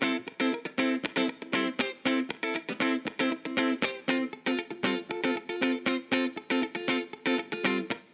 28 Guitar PT2.wav